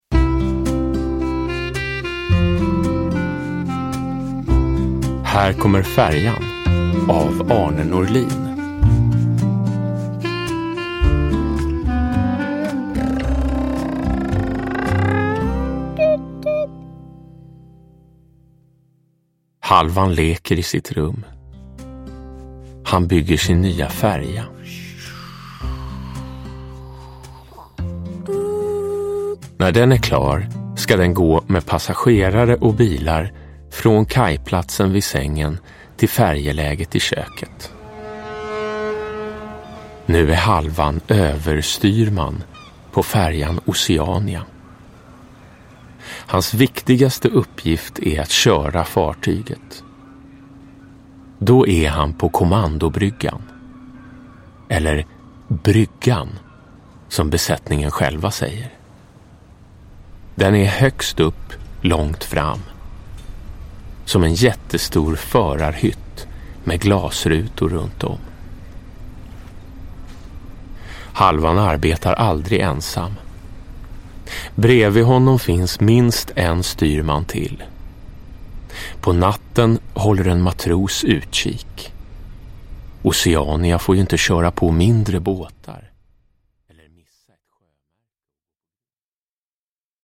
Här kommer färjan – Ljudbok – Laddas ner
Uppläsare: Jonas Karlsson